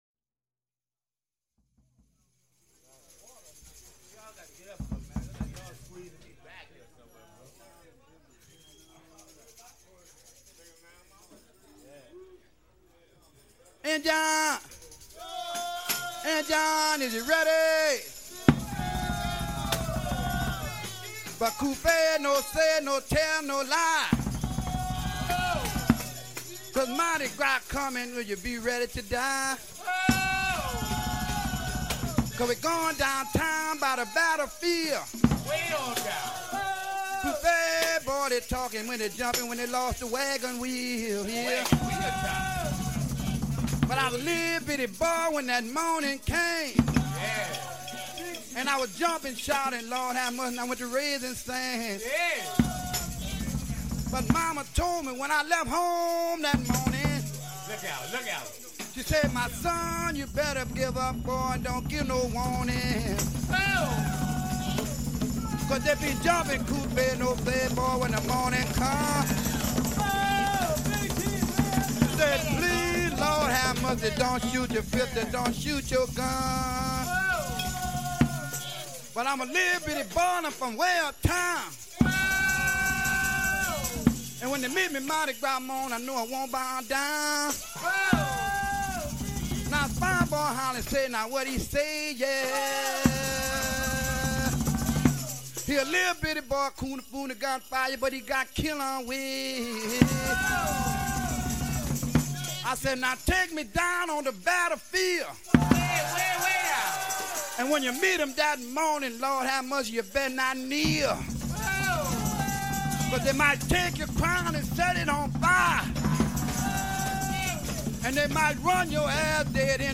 Excerpt of the traditional song